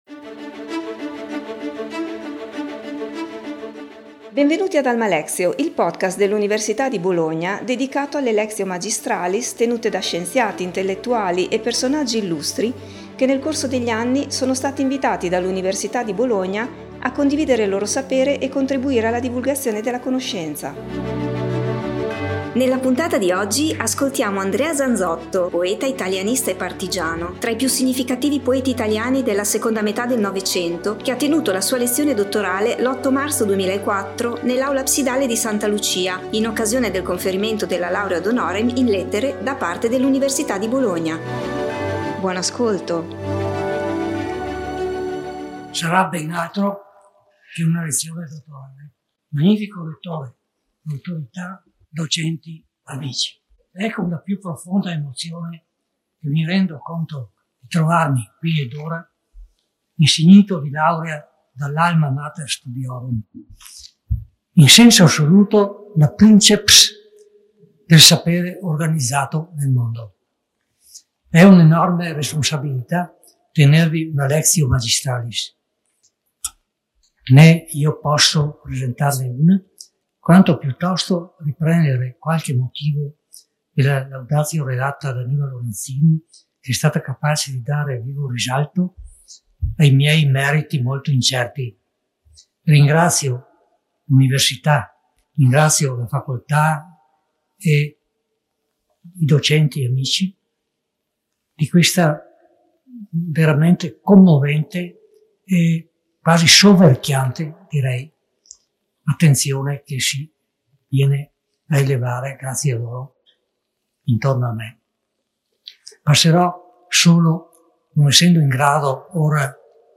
Andrea Zanzotto, che è stato un poeta, un italianista e un partigiano, tra i più significativi poeti italiani della seconda metà del Novecento, ha tenuto la sua lezione dottorale l’8 marzo 2004 nell’Aula Absidale di Santa Lucia in occasione del conferimento della laurea ad honorem in Lettere da parte dell'Università di Bologna.